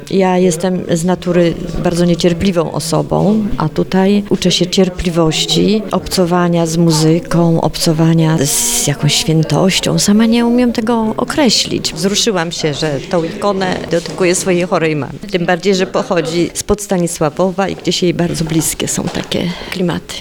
Uczestniczka pisania ikon.mp3